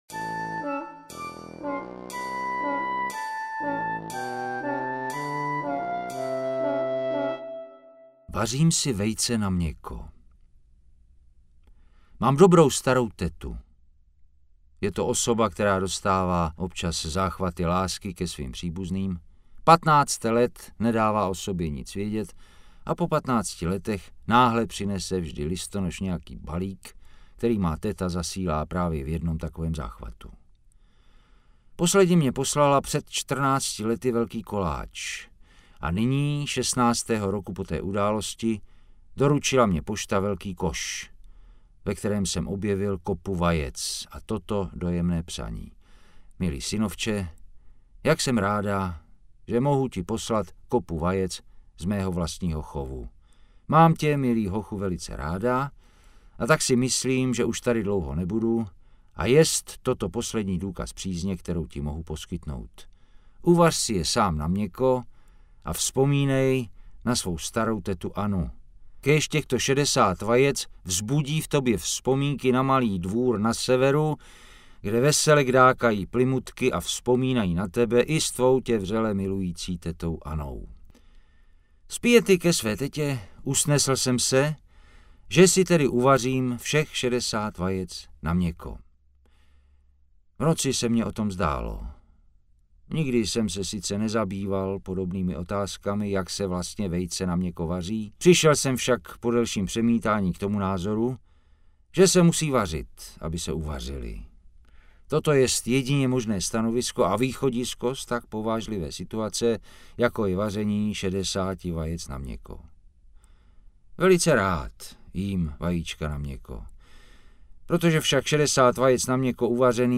Škola humoru audiokniha
Ukázka z knihy
• InterpretJiří Štědroň